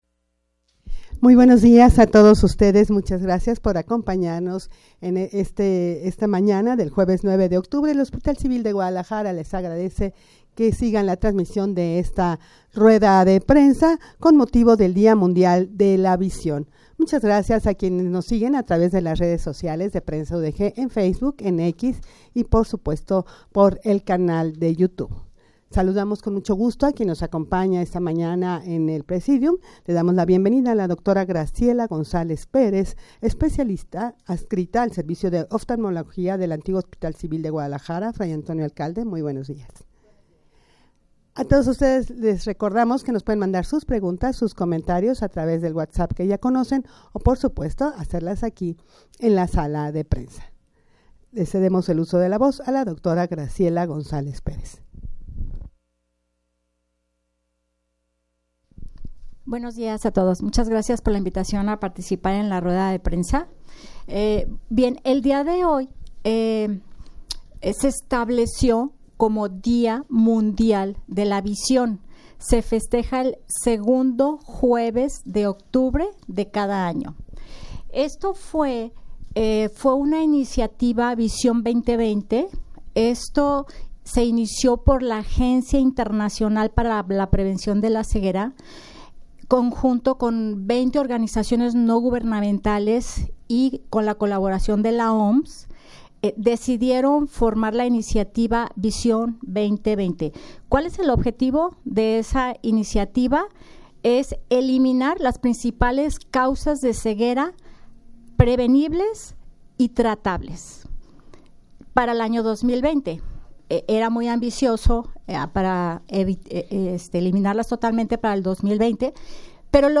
rueda-de-prensa-con-motivo-del-dia-mundial-de-la-vista.mp3